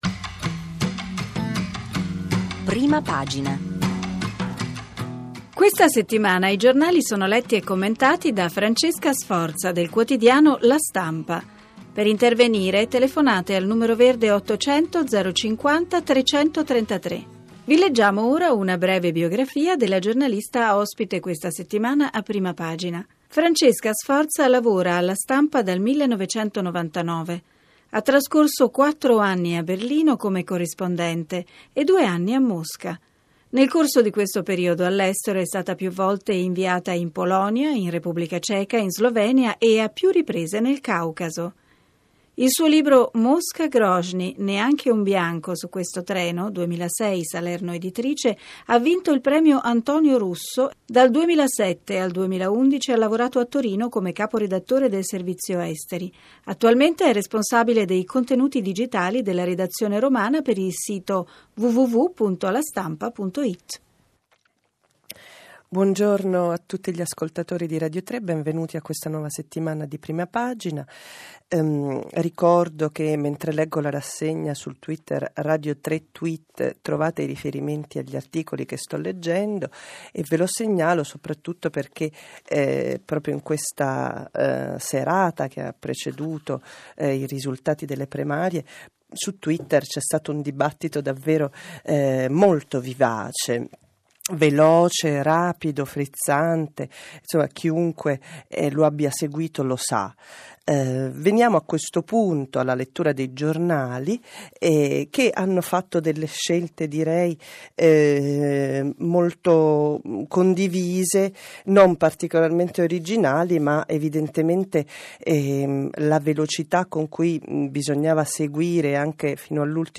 PRIMARIE DEL PD partito democratico, 25 novembre 2012. Rassegna stampa del 26/11/2012